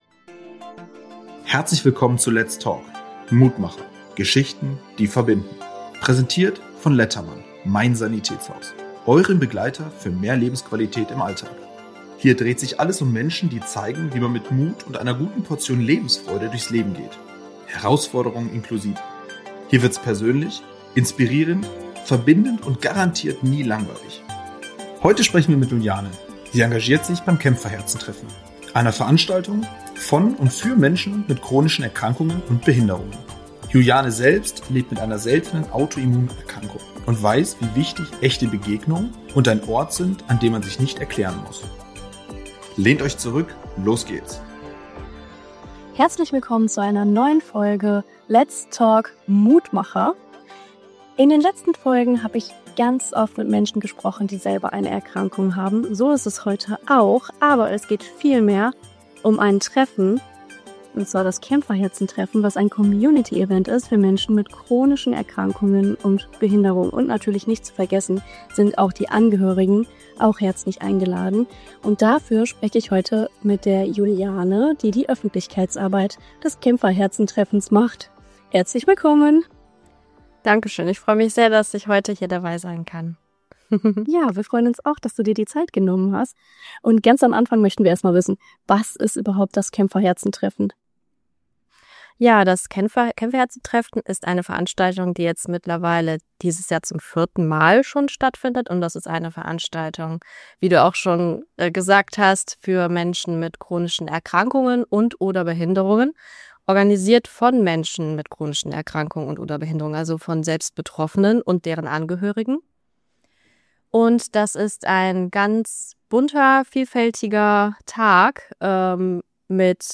In diesem Gespräch spricht sie über ihren Weg und über die große Wirkung eines Tages im Jahr, an dem Menschen zusammenkommen, die sich sonst oft allein fühlen. Die Folge erzählt von berührenden Begegnungen, einer wachsenden Bewegung und dem Gefühl, endlich anzukommen.